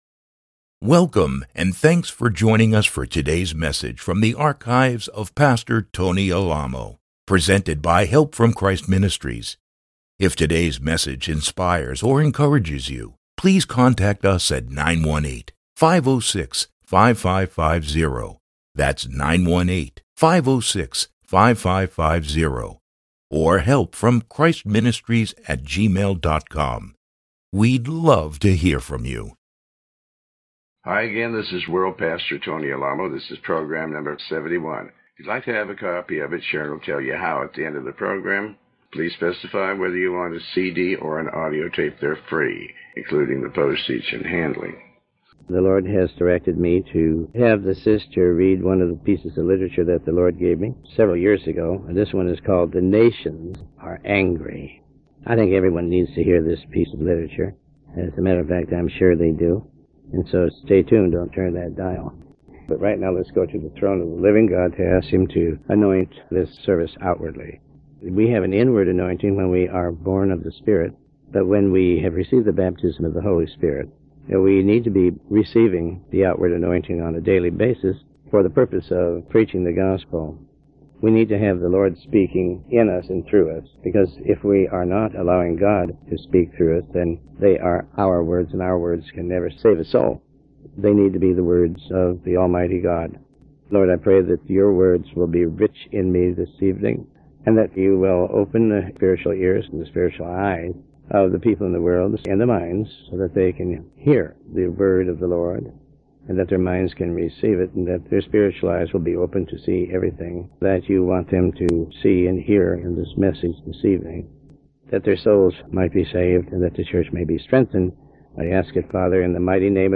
Sermon 71